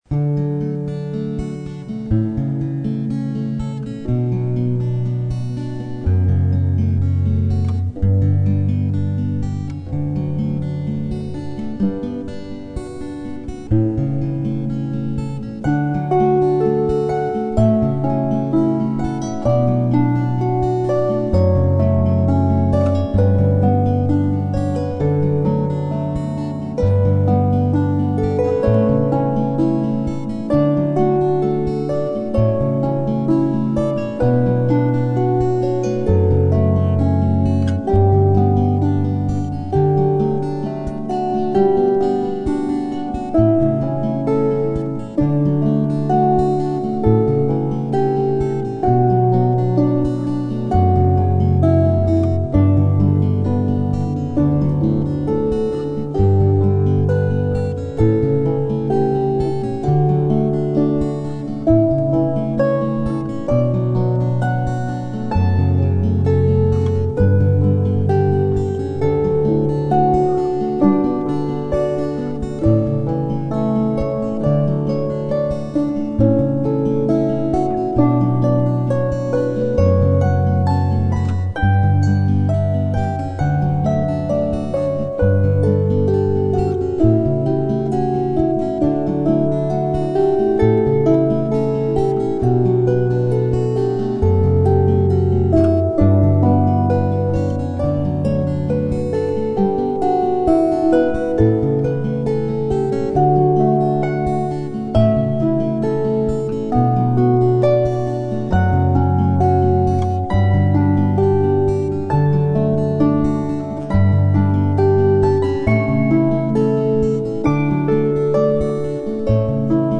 Celtic Twist - traditional Scottish Harp and Guitar duo.
String Musicians